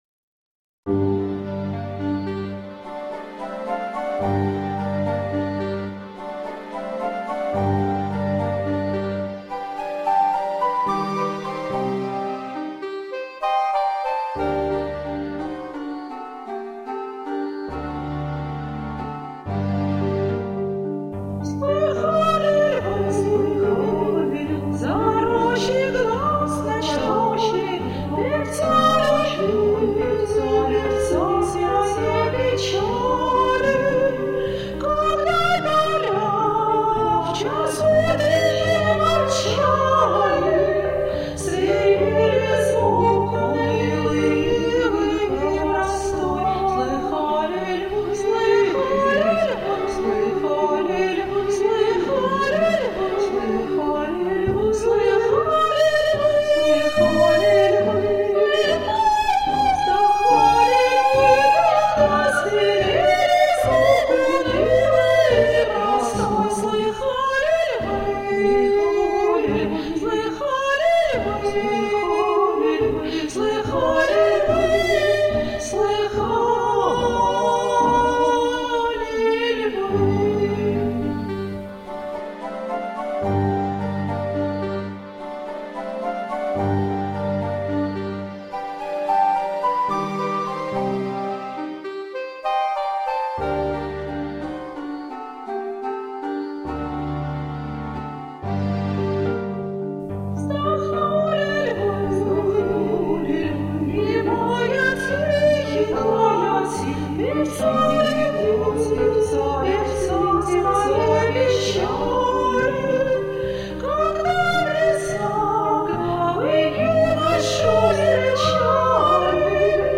анонимный дуэт
Оба дуэта поют всерьез (по нотам, в оригинальной тональности) первый куплет известного дуэта Татьяны и Ольги (второго куплета, как такового, в опере нет, там он превращается в квартет).
Комментарий соперника: Представляю, как секундант, дуэт2 и, соответственно, Татьяну2 (сопрано)и Ольгу2 (меццо-сопрано).